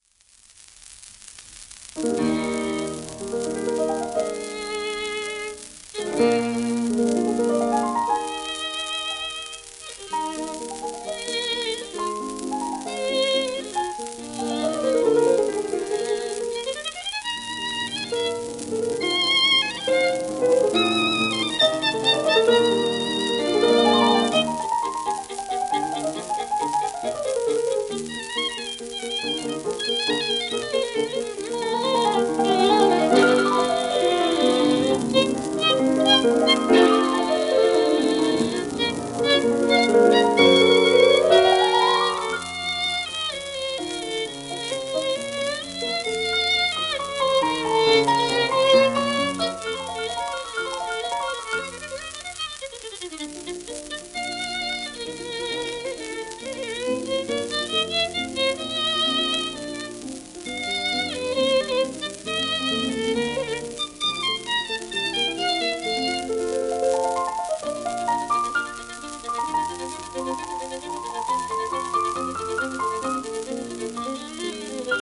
1931年録音